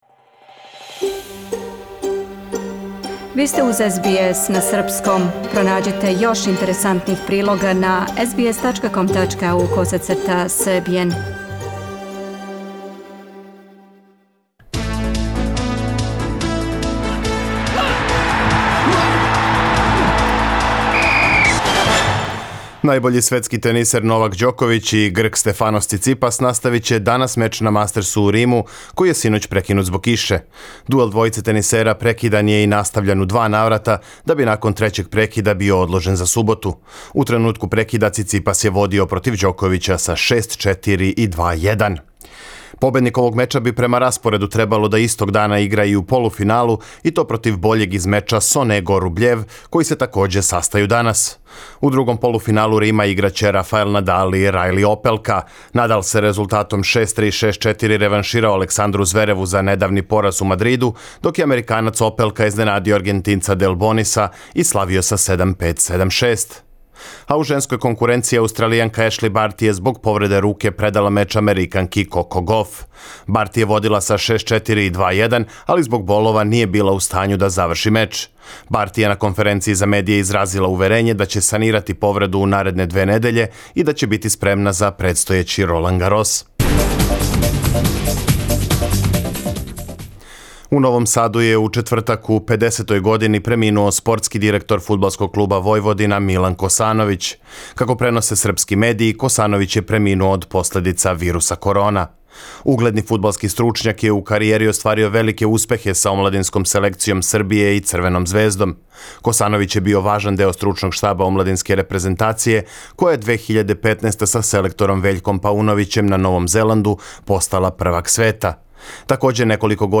Најбољи тенисер света мора да надокнади велику предност Стефаноса Циципаса (6:4, 2:1), коју је Грк стекао у петак пре него што је меч прекинут због јаке кише. У спортским вестима сазнајте и колико је фудбалерима Црвене звезде потребно да изједначе или оборе рекорд Партизана по броју постигнутих голова у једној сезони. Такође, због пандемије је отказана трка Формуле 1 у Турској, а у недељу у Београду почиње финале плеј-офа регионалне кошаркашке АБА лиге.